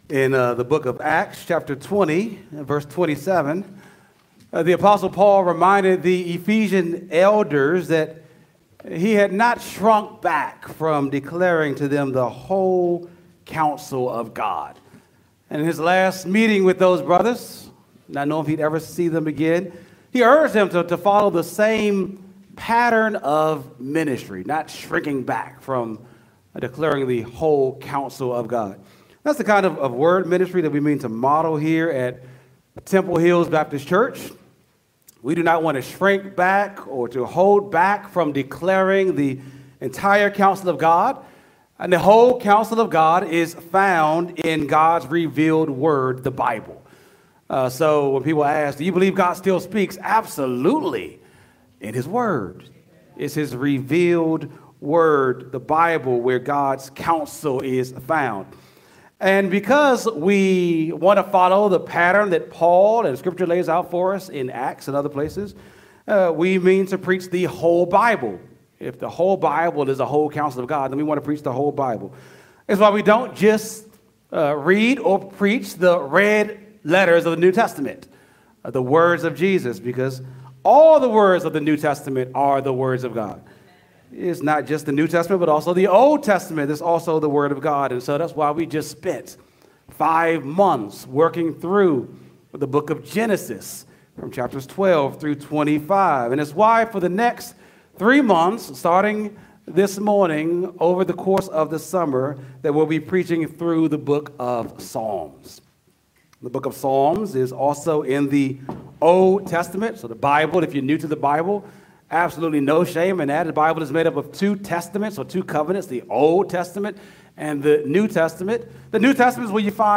sunday-sermon-6-1-25.mp3